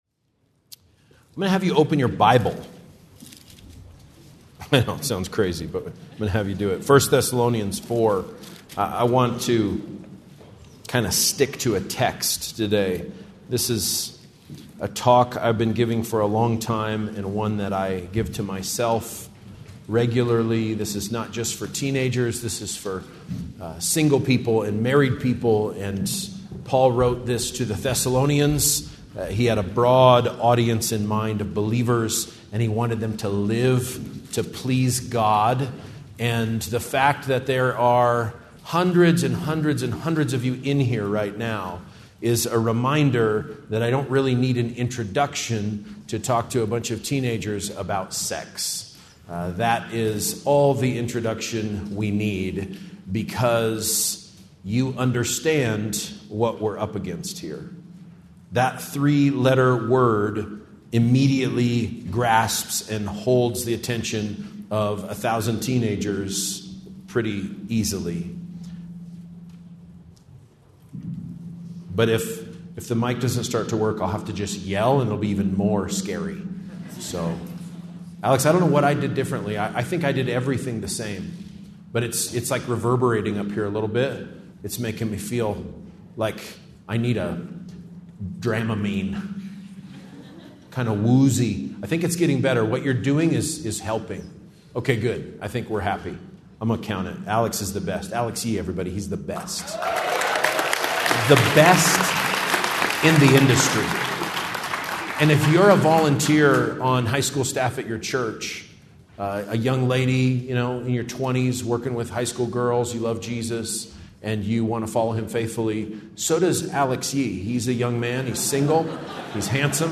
Breakout Session: Teens Should Keep Their Way Pure